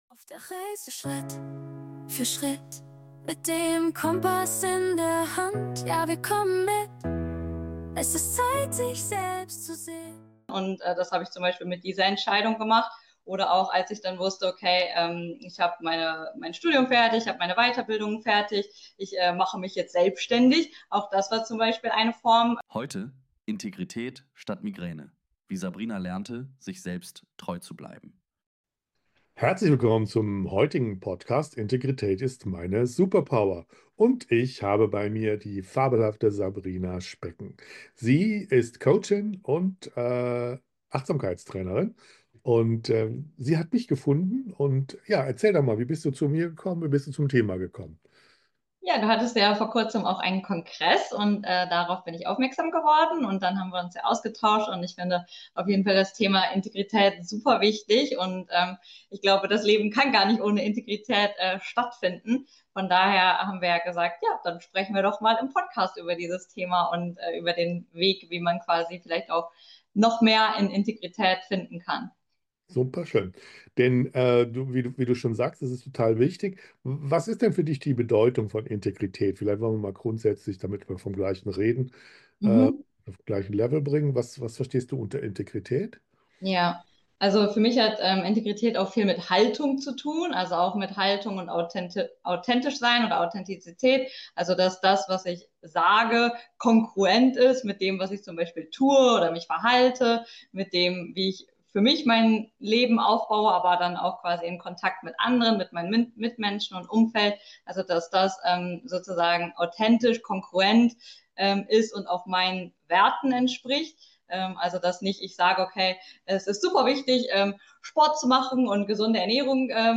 Es ist ein ehrlicher, berührender Austausch zweier Menschen, die nicht nur vom Leben reden, sondern es leben – mit allen Brüchen, Zweifeln und Erkenntnissen.